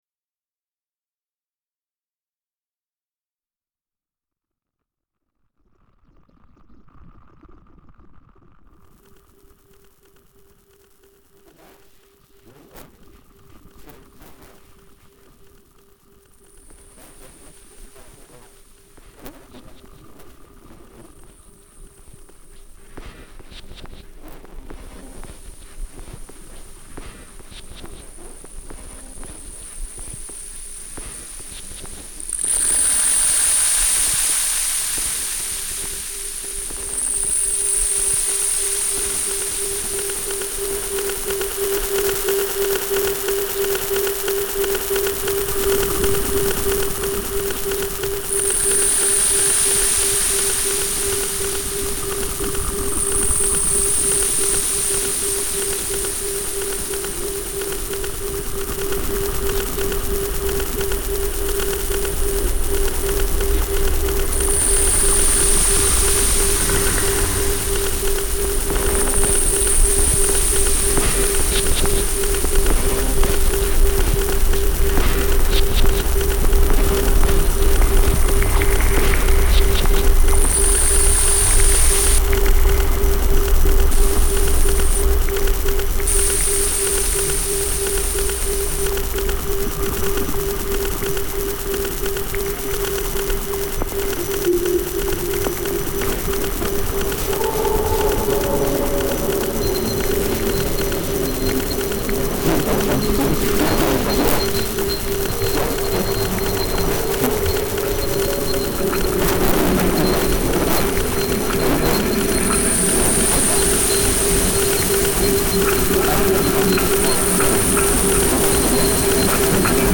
resulting in very abstract and experimental tracks